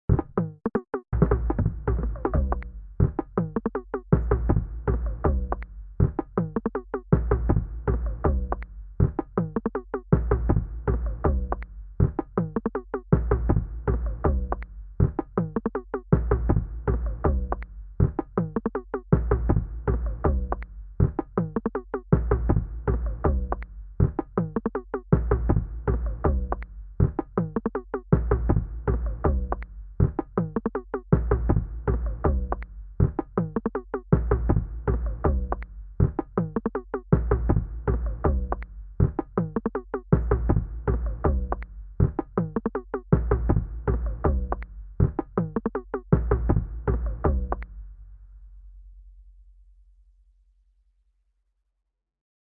描述：鼓节奏样本序列击鼓敲击心律失常节奏
Tag: 韵律 打击乐器 样品 心律不齐 心跳 序列 有节奏